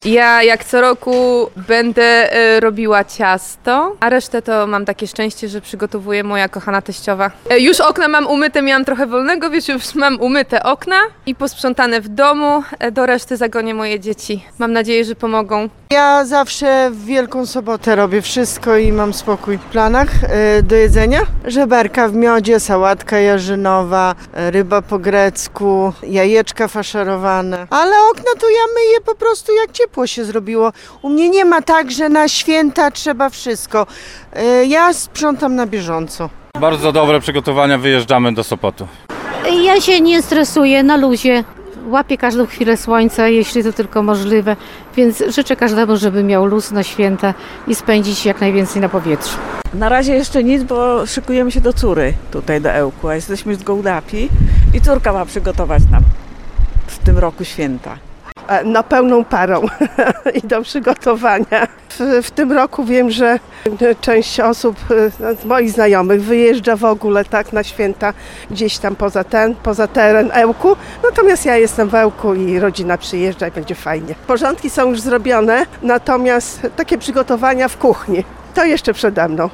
Spytaliśmy mieszkańców regionu.